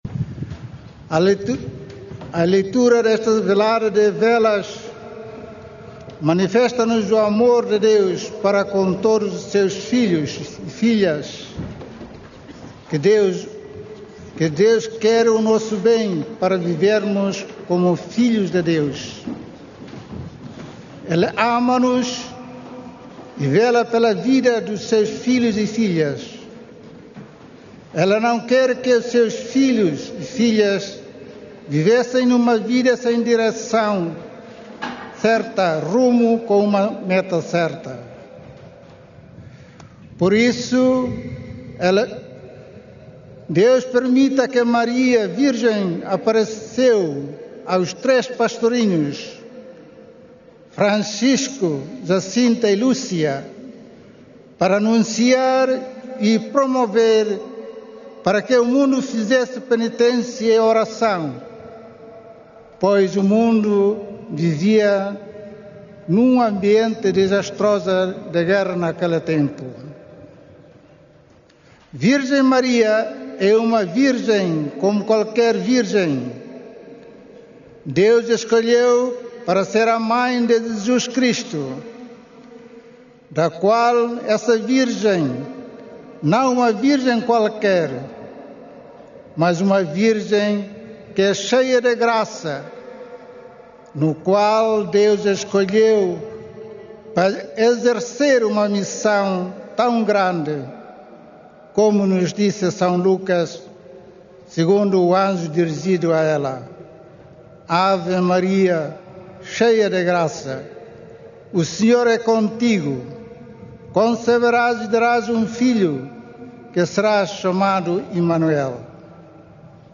Na homilia da celebração da Palavra deste 12 de setembro, o bispo timorense exortou os peregrinos a seguir as virtudes exemplares de Nossa Senhora.
Cerca de 10 mil peregrinos participaram nas celebrações desta noite, na Cova da Iria, que marcaram o início da Peregrinação Internacional Aniversária de setembro.
homilia-12-noite.mp3